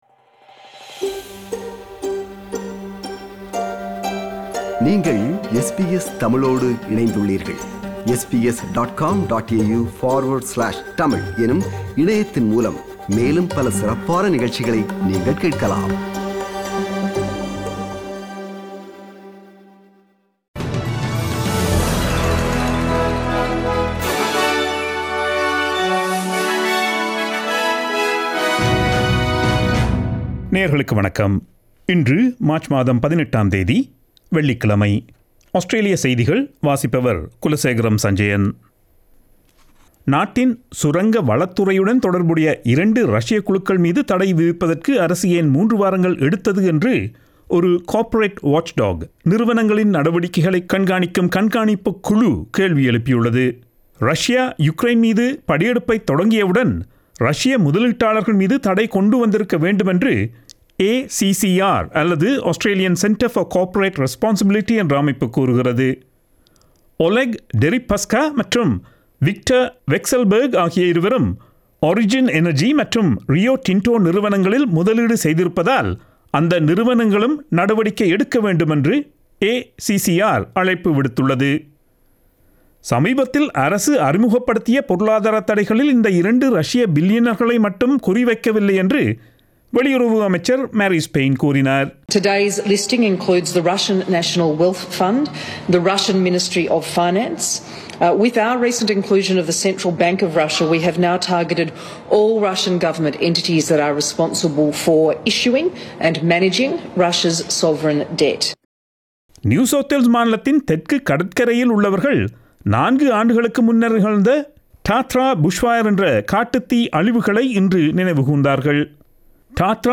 Australian news bulletin for Friday 18 March 2022.